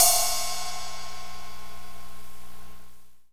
Index of /90_sSampleCDs/Northstar - Drumscapes Roland/CYM_Cymbals 3/CYM_P_C Cyms x